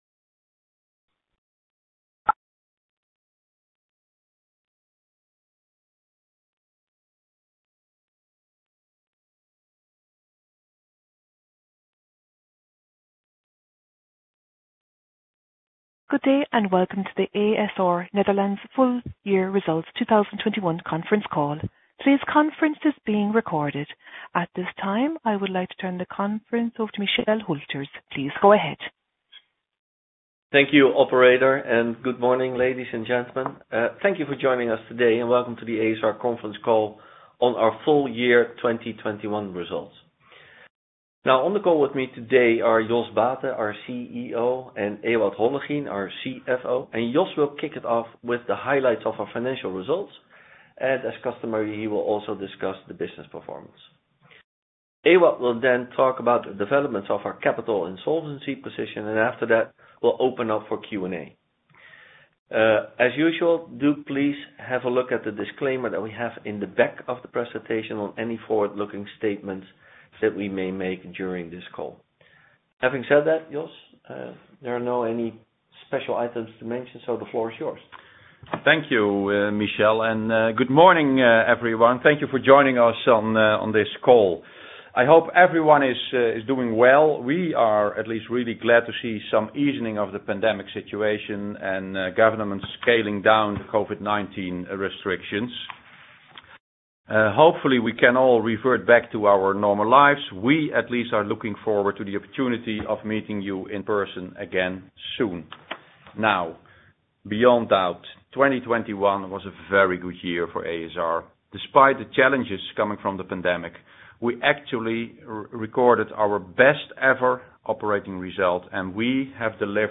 Investor conference call